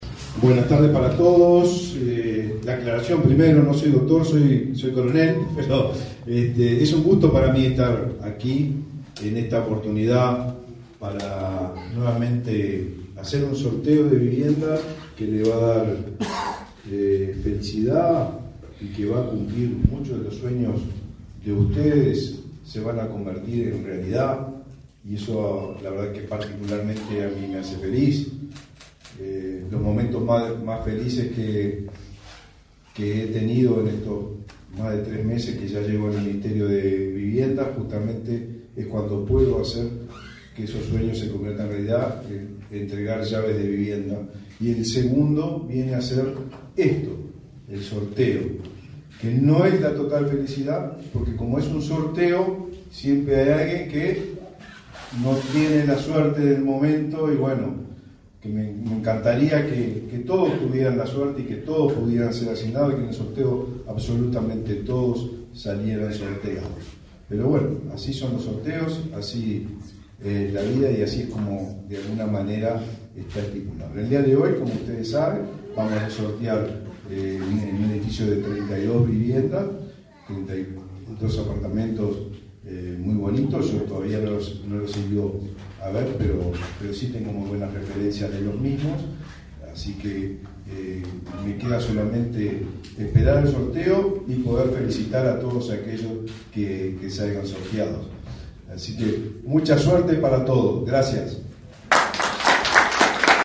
Palabras del titular del MVOT, Raúl Lozano
En el evento, el ministro Raúl Lozano realizó declaraciones.